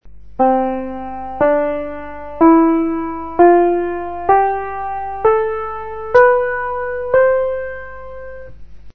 Sur le clavier d'un piano, après le si revient le do : cela fait 8 notes; on parle d'octave (du latin octavus = huitième).
diatonique.mp3